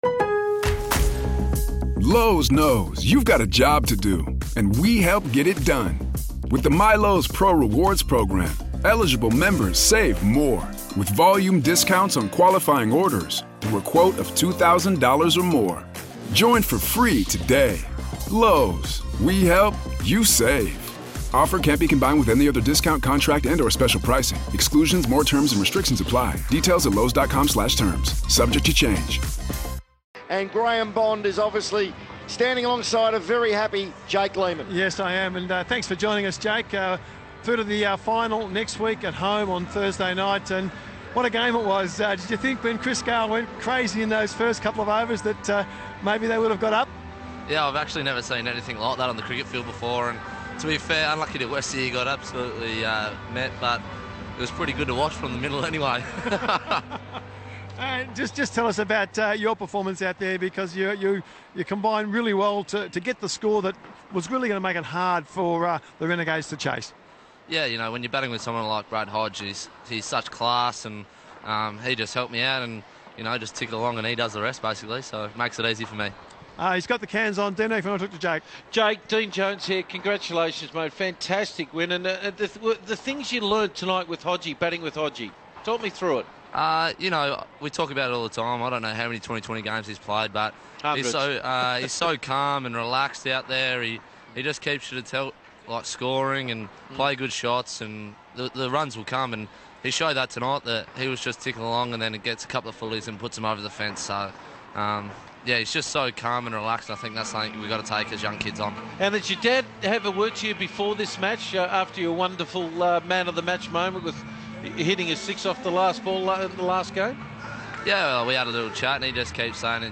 INTERVIEW: Jake Lehmann chats after the Adelaide Strikers victory over the Melbourne Renegades